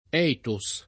ethos [ $ to S ] (raro etos [id.]) s. m.